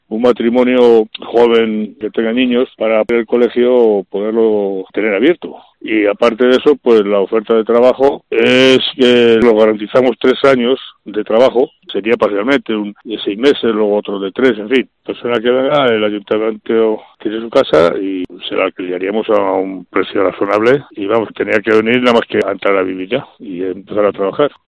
El Alcalde señala la oferta con un requisito imprescindible.